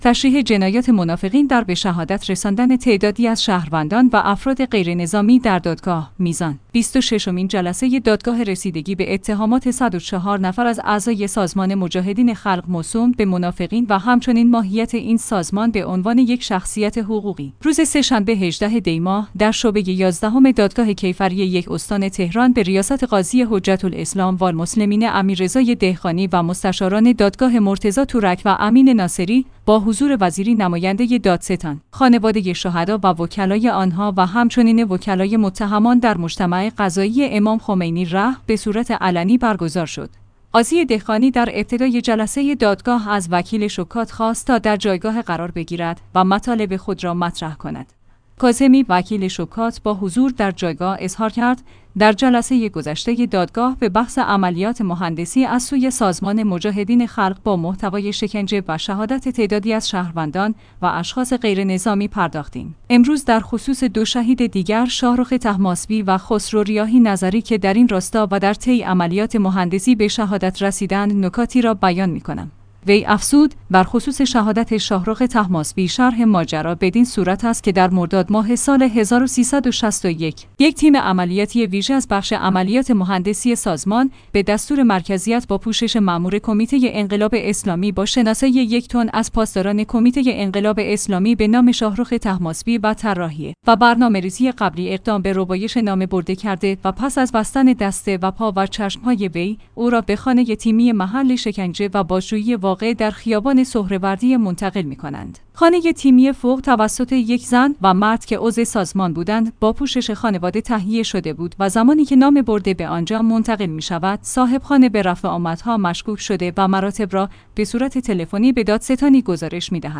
میزان/ بیست و ششمین جلسه دادگاه رسیدگی به اتهامات ۱۰۴ نفر از اعضای سازمان مجاهدین خلق موسوم به منافقین و همچنین ماهیت این سازمان به عنوان یک شخصیت حقوقی، روز سه‌شنبه (۱۸ دی‌ماه) در شعبه یازدهم دادگاه کیفری یک استان تهران به ریاست قاضی حجت‌الاسلام والمسلمین امیررضا دهقانی و مستشاران دادگاه مرتضی تورک